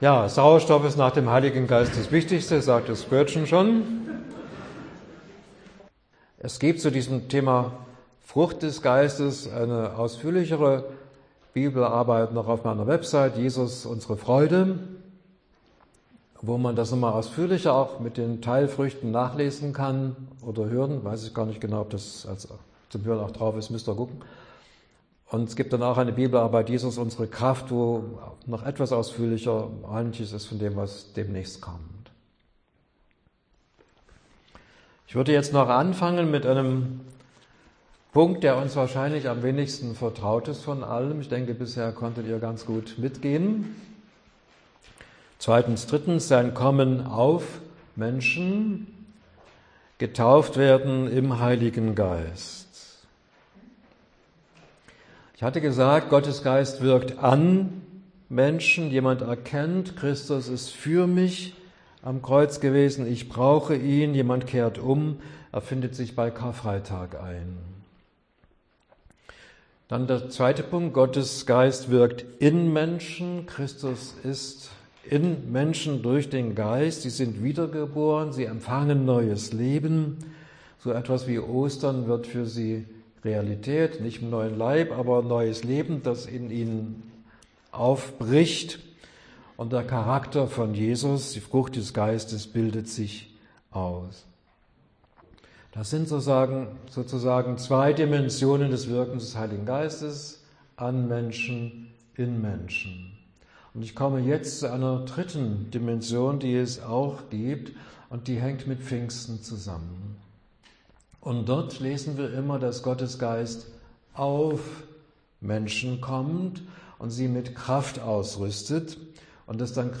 Dauer des Vortrages: ca. 34 Minuten